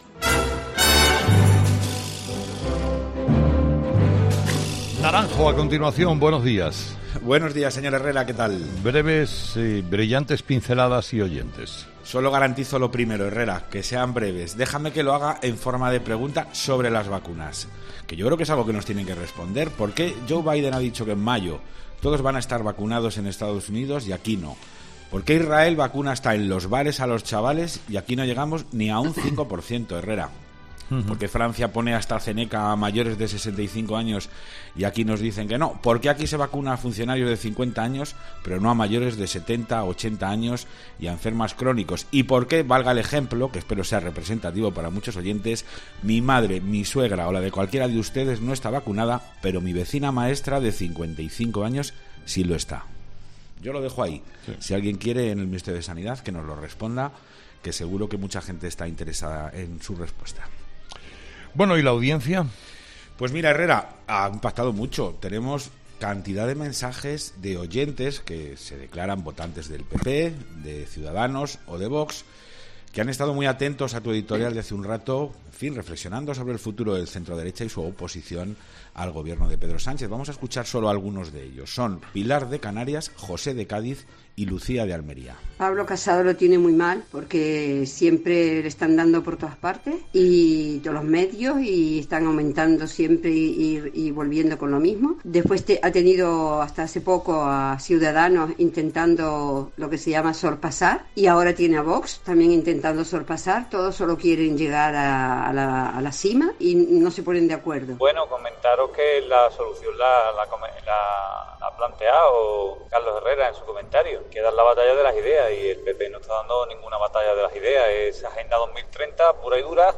Los oyentes, de nuevo, protagonistas en 'Herrera en COPE' con su particular tertulia.